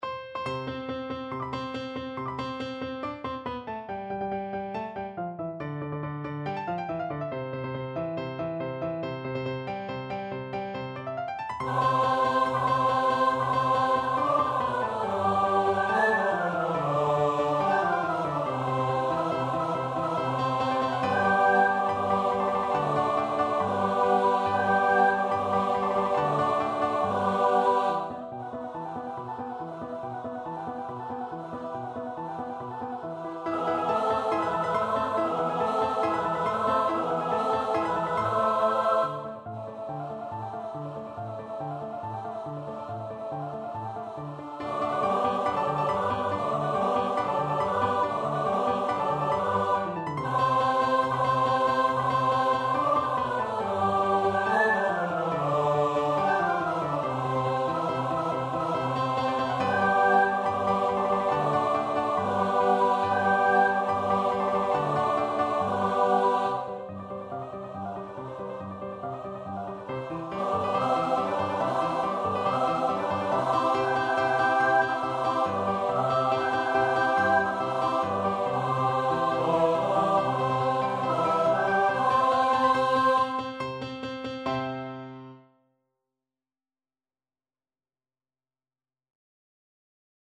~ = 140 Allegro vivace (View more music marked Allegro)
2/4 (View more 2/4 Music)
Choir  (View more Easy Choir Music)
Classical (View more Classical Choir Music)